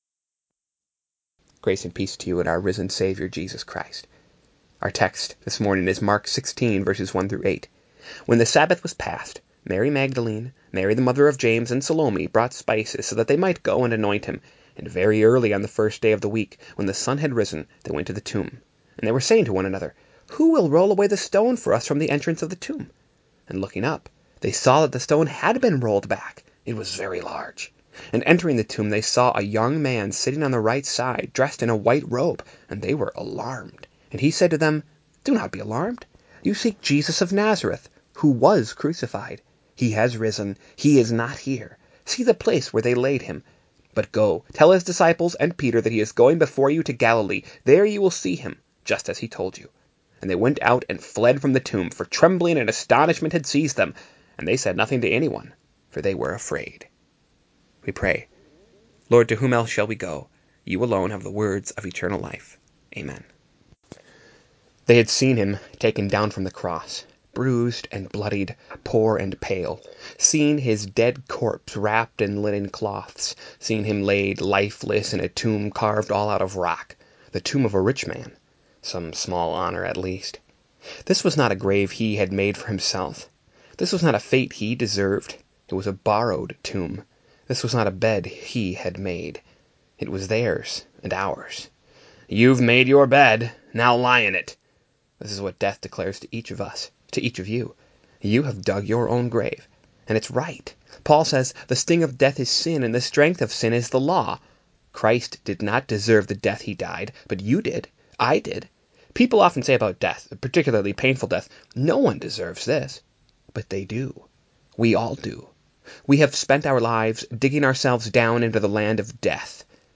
2020-04-23 ILC Chapel — The Empty Grave of Jesus…